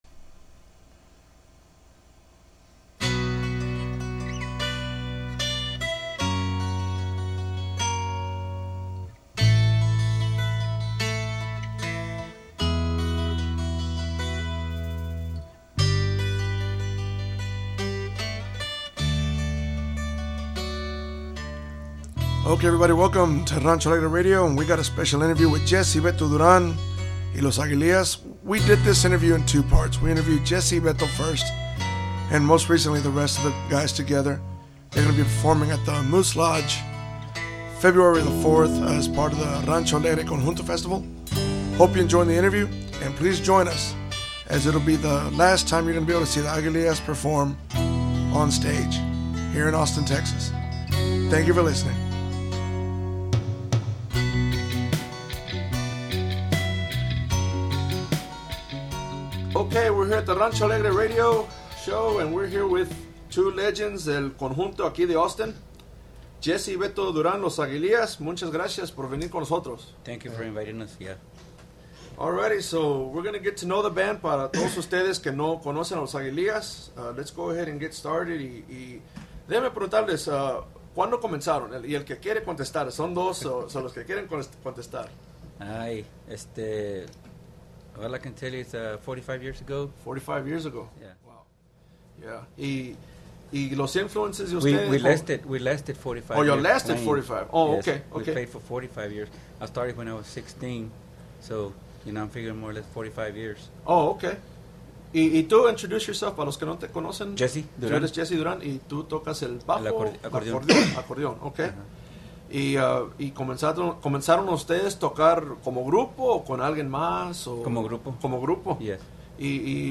Rancho Alegre Interview